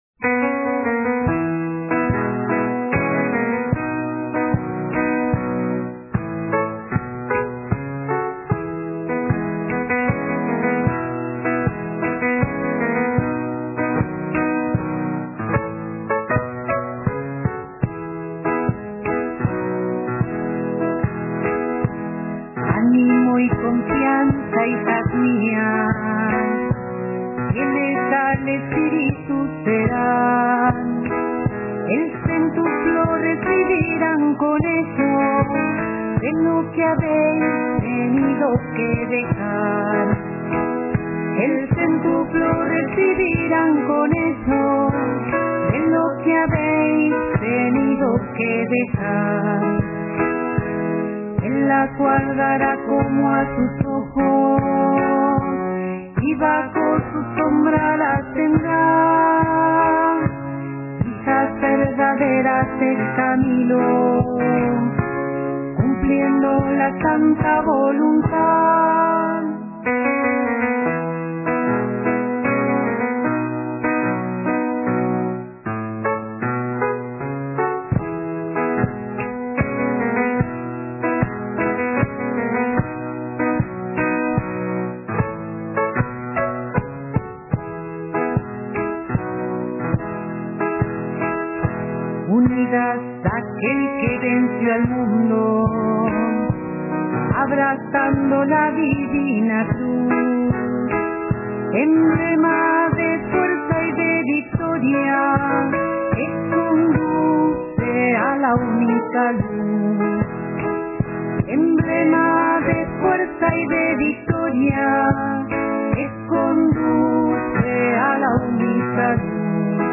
Recomendaciones (Zamba) Animo y confianza hijas mías fieles al espíritu serán el céntuplo recibirán con ello de lo que habéis tenido que dejar.